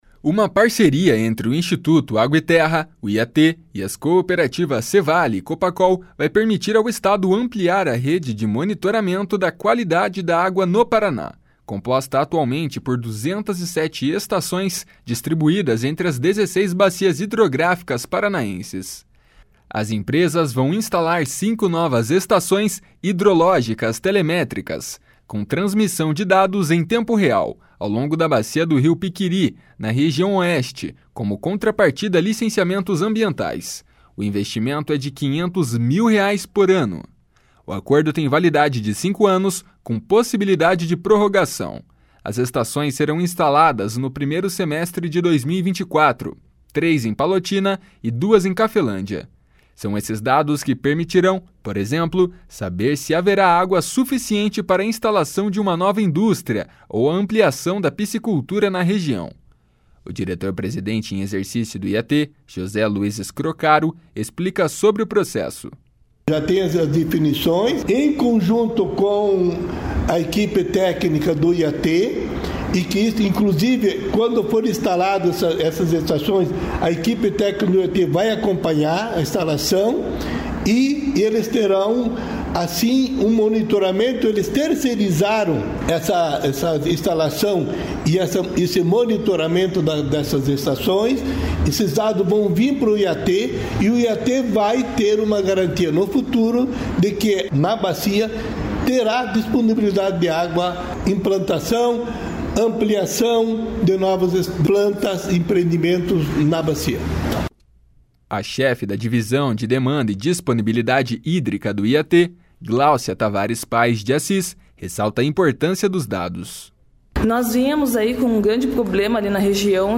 O diretor-presidente em exercício do IAT, José Luiz Scroccaro, explica sobre o processo.